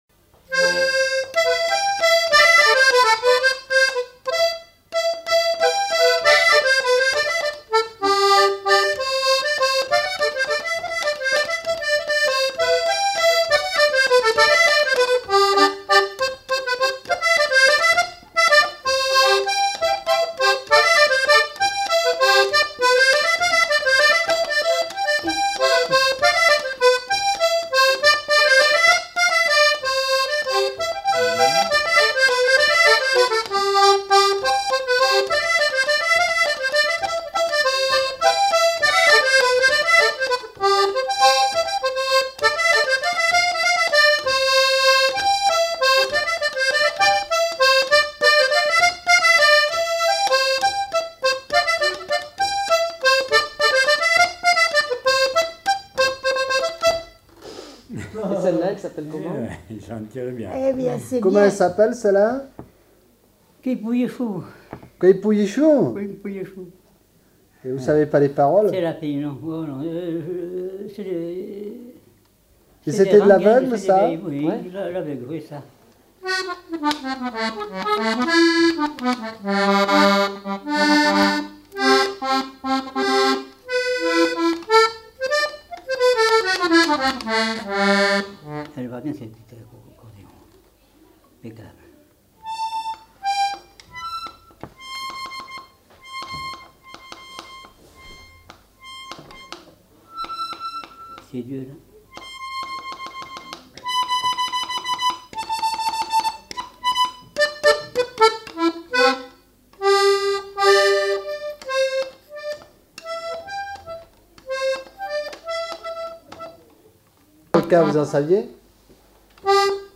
Lieu : Meymac
Genre : morceau instrumental
Instrument de musique : accordéon
Notes consultables : Seule l'annonce est jouée. Essais d'autres mélodies en fin de séquence.